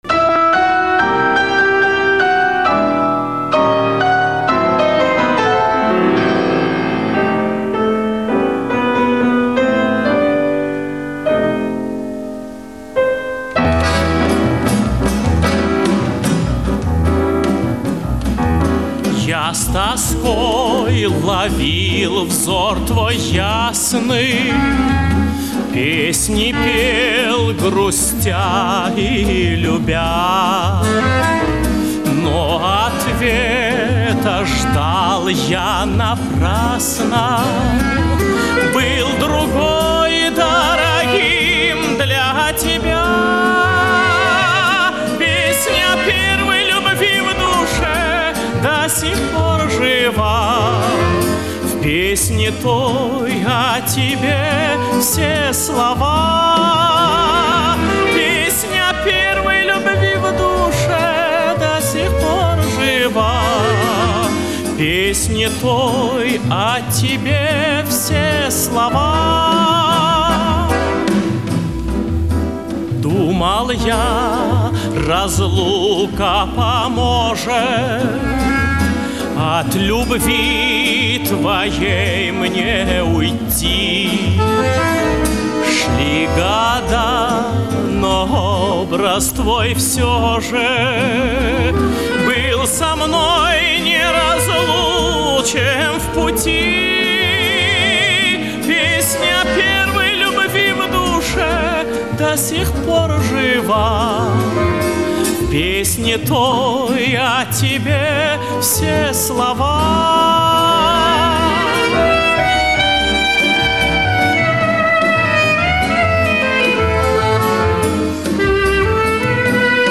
Битрейт ниже,а качество лучше.